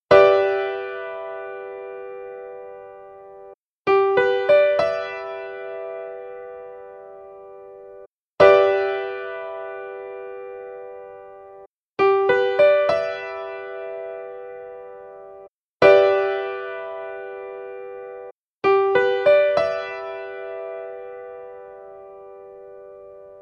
G6 Piano Chord
G6-Piano-Chord.mp3